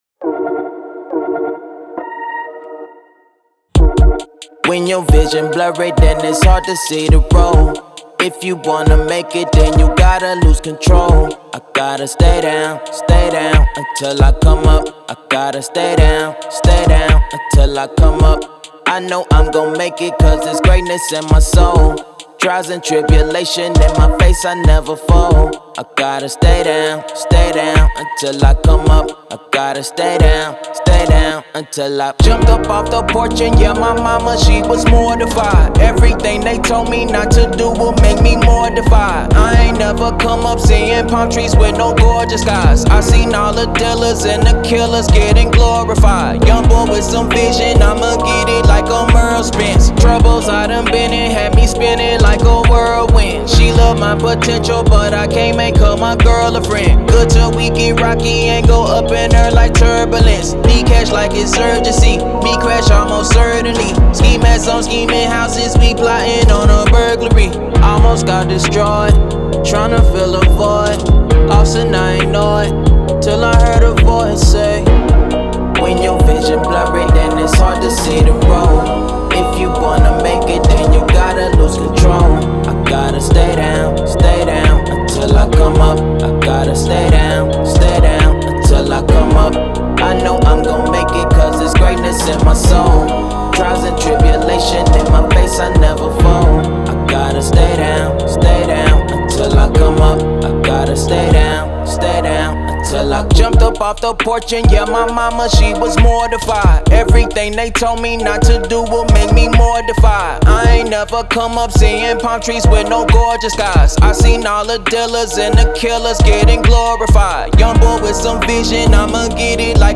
Hip Hop, Rap
Eb Minor
Triumph, Motivation